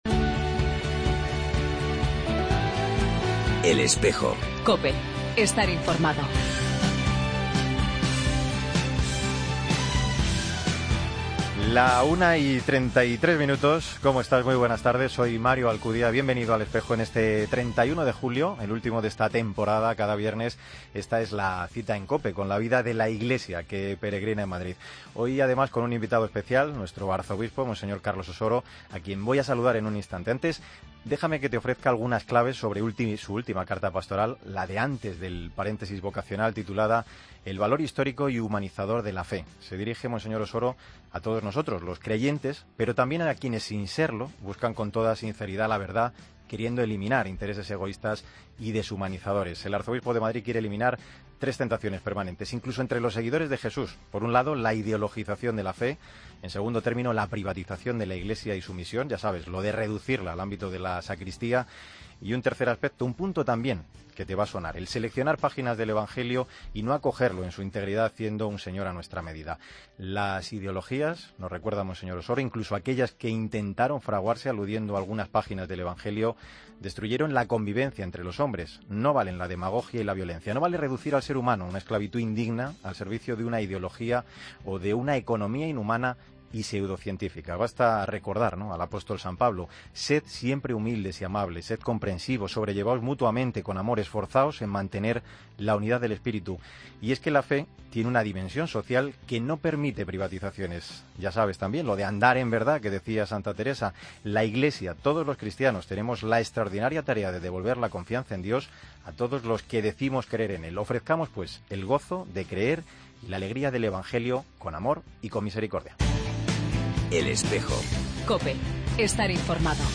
AUDIO: Entrevista al arzobispo de Madrid, monseñor Carlos Osoro, tras su primer año como arzobispo de Madrid, con quien hacemos balance de este...